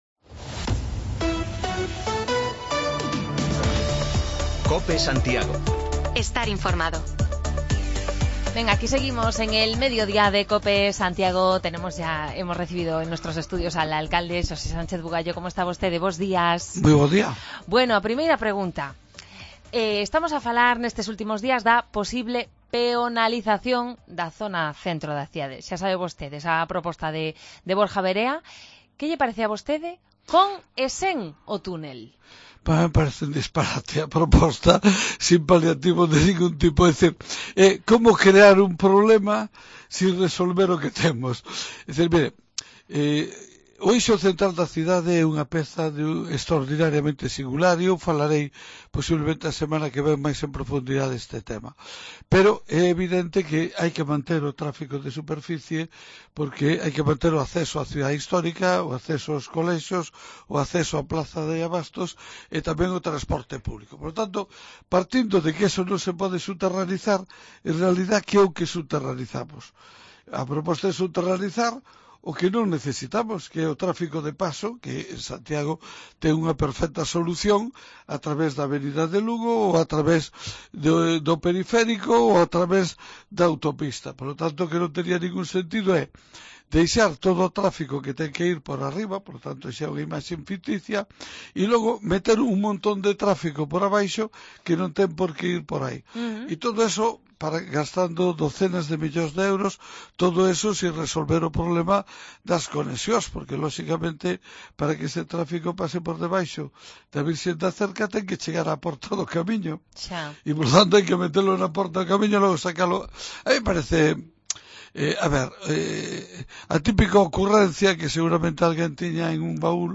AUDIO: Repasamos la actualidad municipal y de precampaña con el alcalde y candidato a la reelección en Santiago Sánchez Bugallo